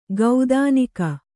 ♪ gaudānika